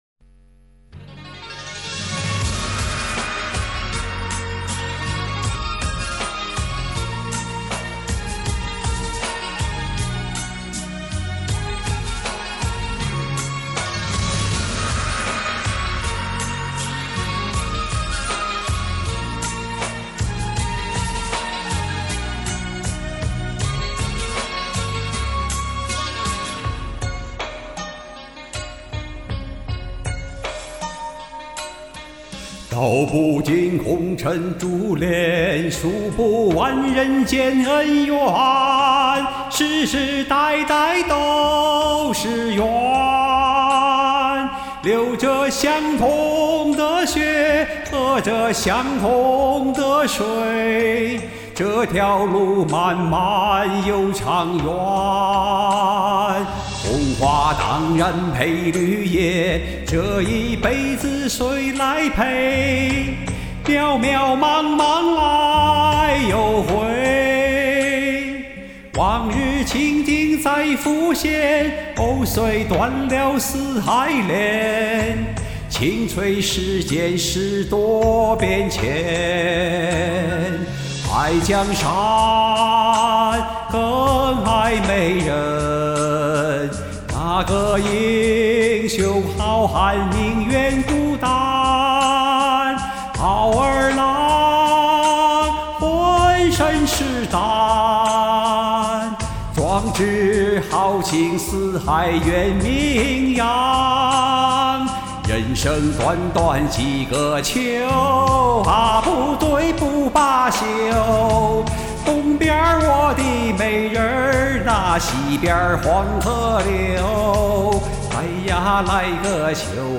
男声用原伴奏有点高不成低不就的感觉。用升降调演绎了两个不同风格的版本。